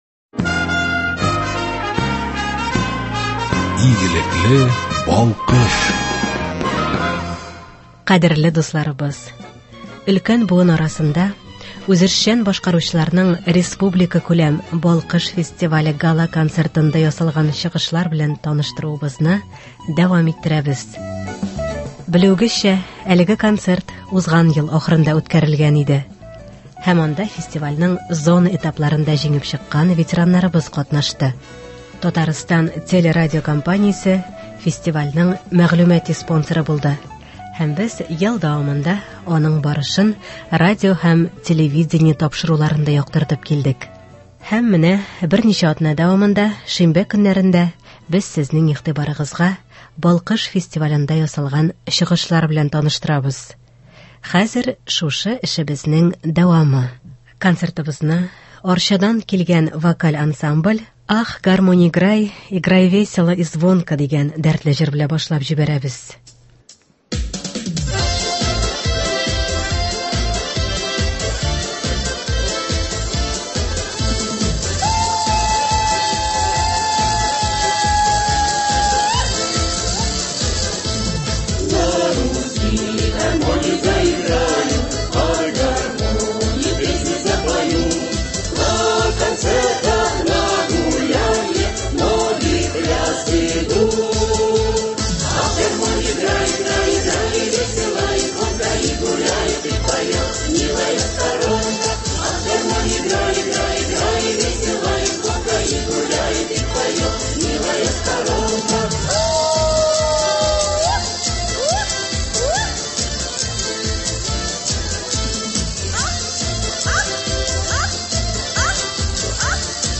Концерт.
Кадерле дусларыбыз, өлкән буын арасында үзешчән башкаручыларның республикакүләм «Балкыш» фестивале Гала-концертында ясалган чыгышлар белән таныштыруыбызны дәвам иттерәбез.
Һәм анда фестивальнең зона этапларында җиңеп чыккан ветераннарбыз катнашты.